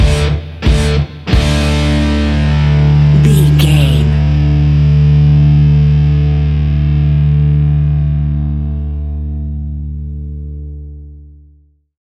Epic / Action
Fast paced
Ionian/Major
D
hard rock
punk metal
instrumentals
Rock Bass
heavy drums
distorted guitars
hammond organ